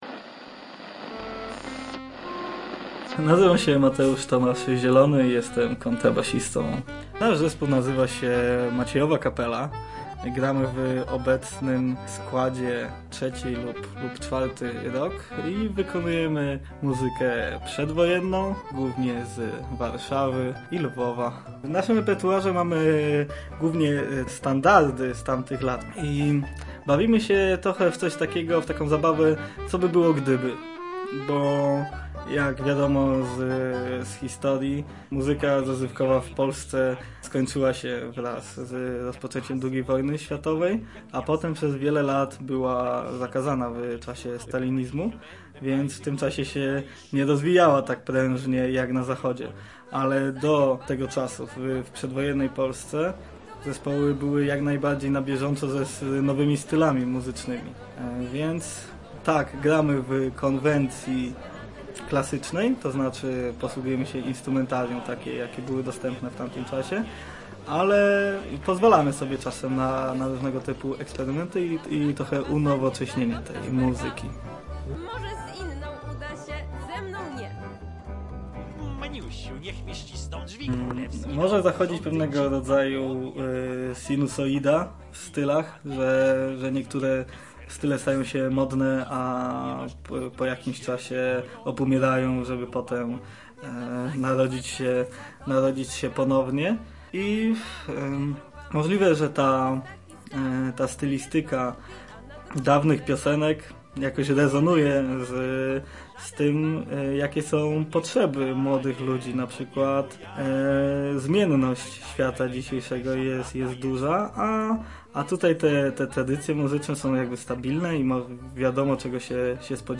Na te oraz inne pytania, dotyczące swoistego renesansu w brzmieniach odpowiedzą nam artyści, którzy propagują taką stylistykę w swojej twórczości.